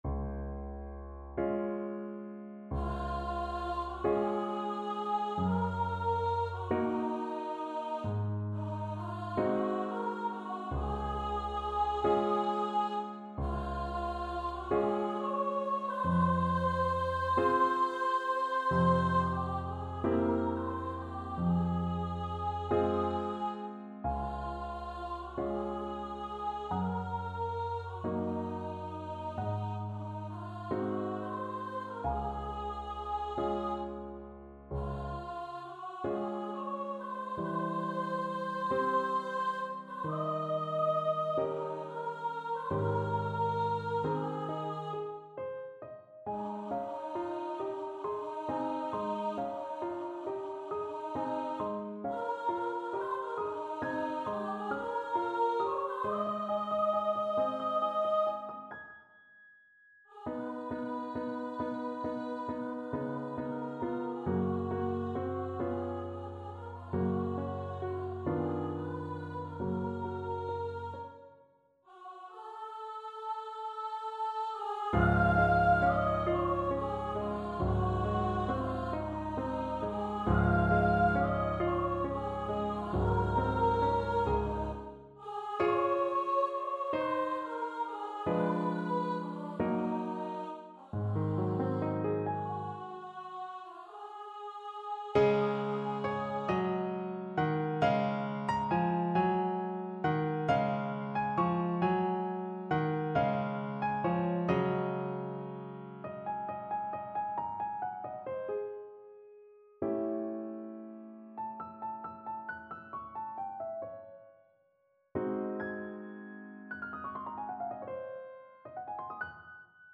Andante .=45
6/8 (View more 6/8 Music)
Classical (View more Classical Mezzo Soprano Voice Music)